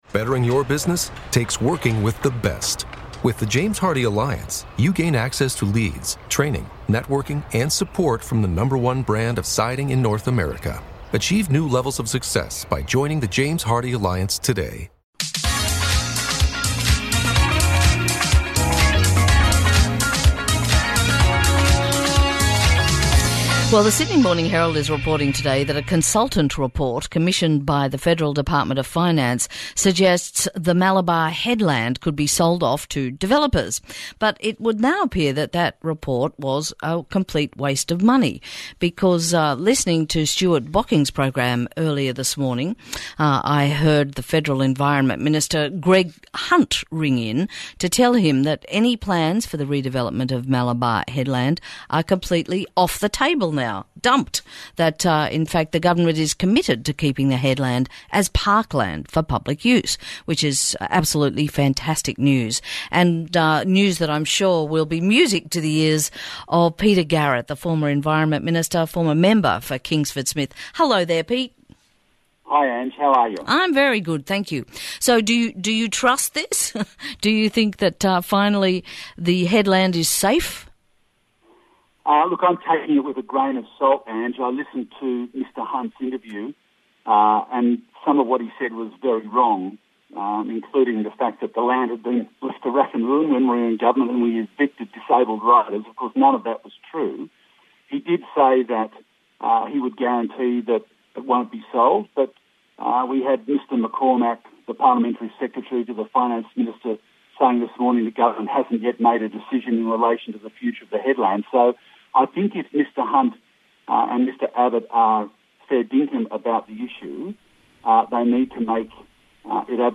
Former Environment Minister & Malabar Local Peter Garrett speaks with Angela Catterns about the to suggestions that Malabar Head could be developed. Is there any need for more national parks?